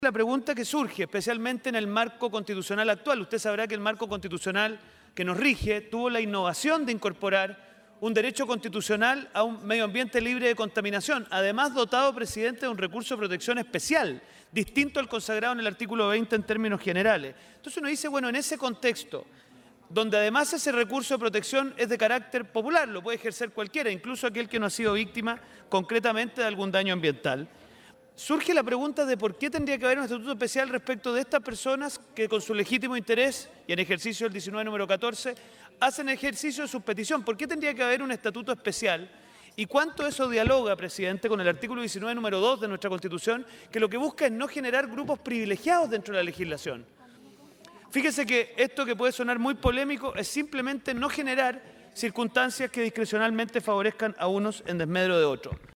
Asimismo, el diputado Diego Schalper, también de RN, hizo referencia a la Constitución de la República señalando que la Carta Magna busca “no generar grupos privilegiados dentro de la legislación”.